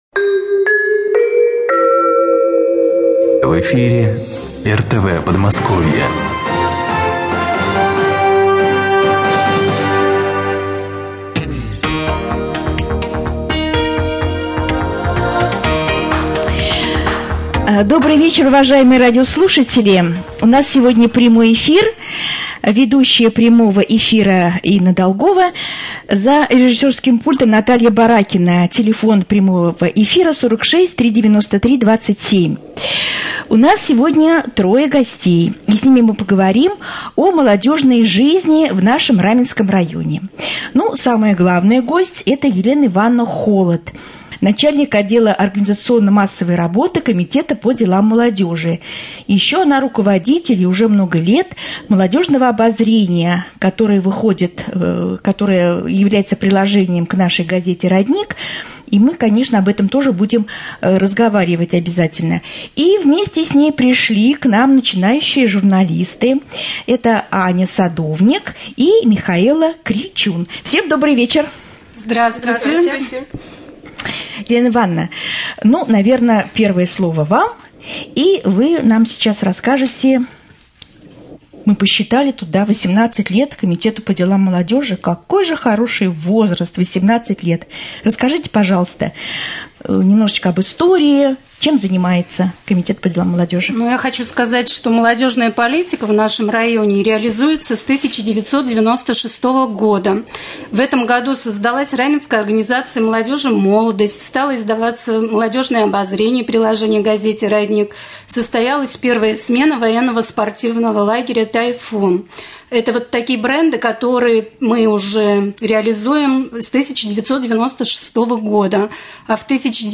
1.Прямой-эфир1.mp3